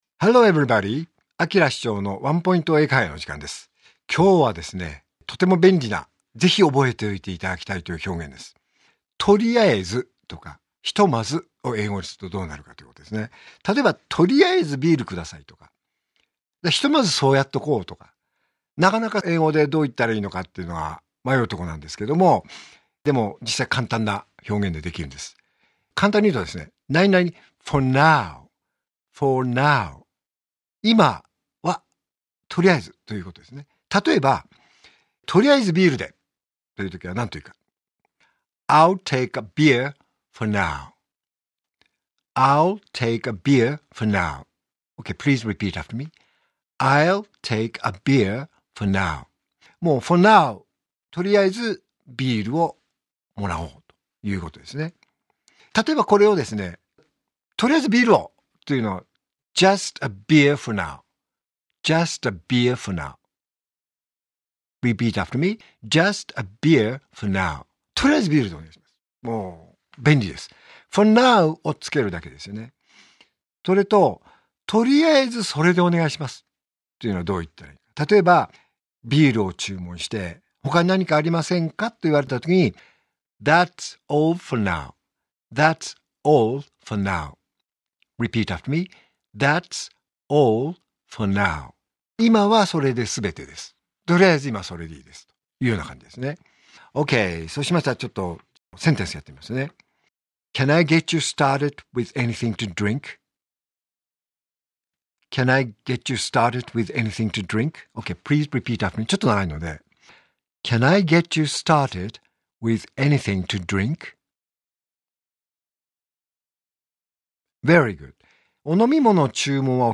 R5.5 AKILA市長のワンポイント英会話